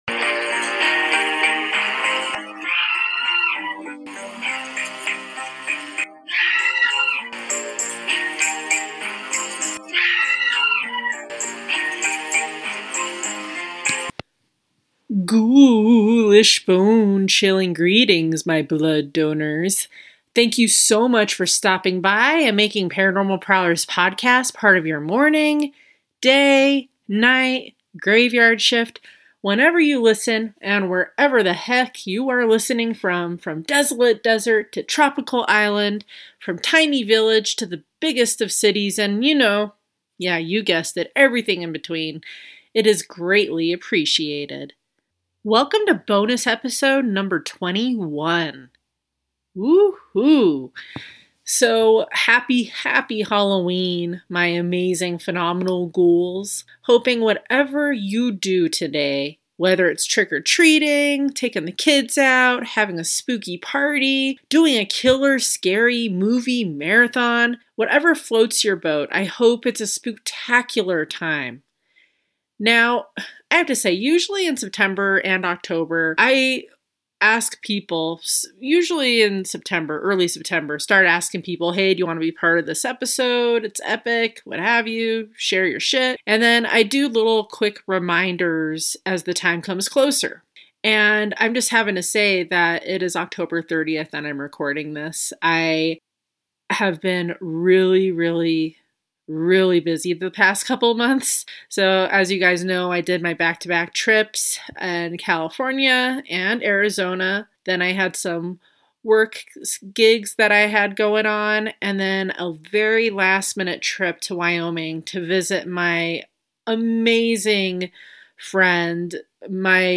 In this ghoulishly amazing episode three phenomenal ladies share their experiences!